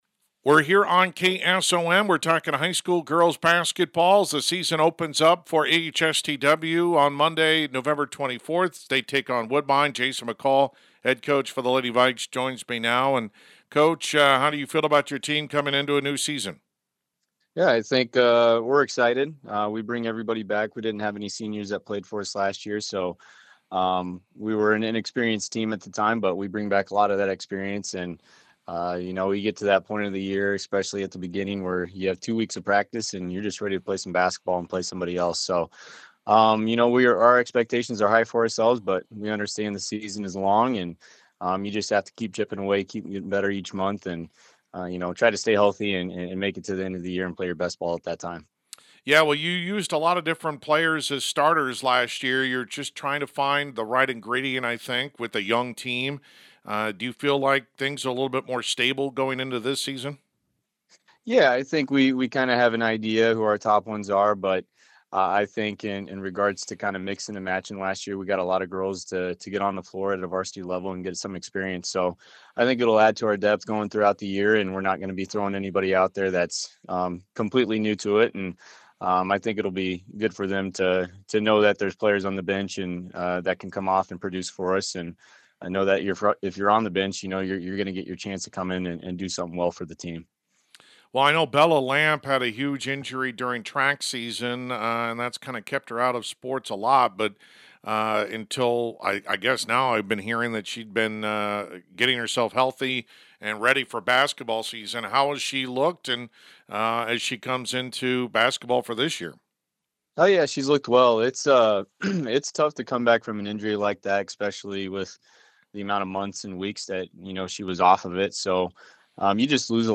Complete Interview